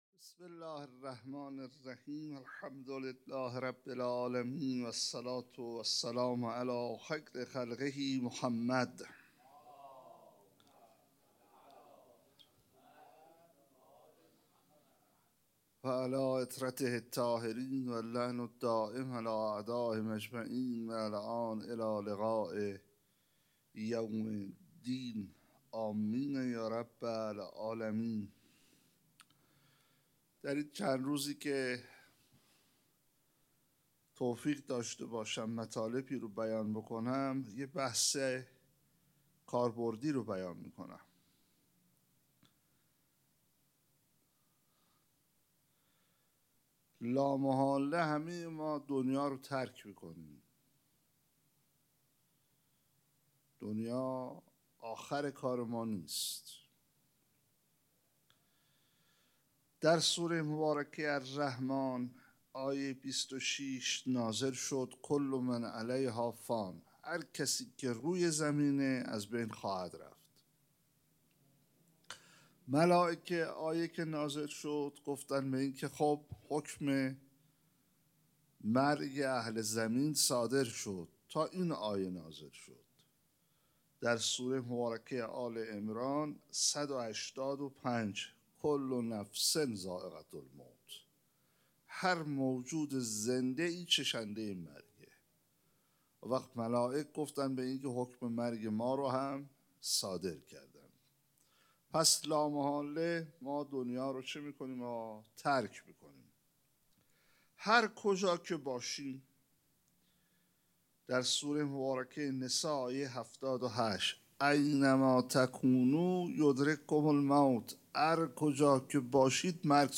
حسینیه انصارالحسین علیه السلام